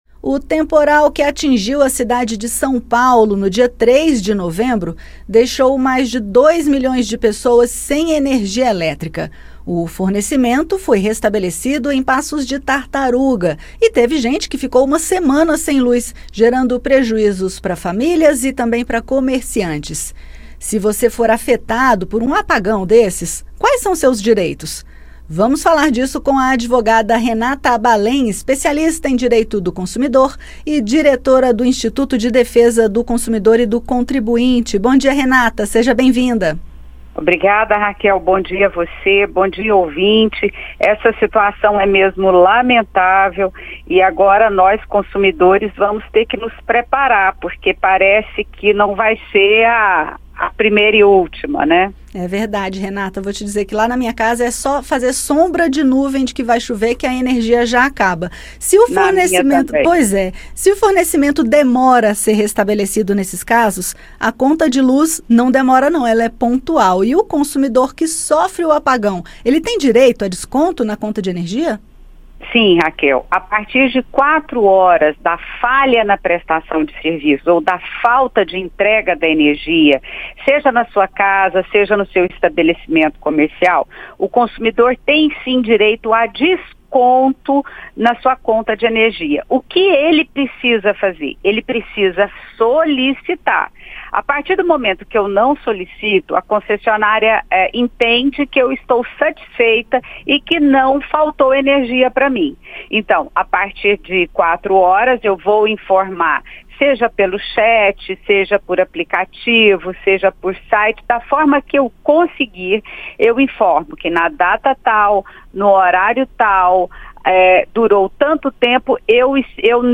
Advogada fala sobre os direitos do consumidor no caso de apagão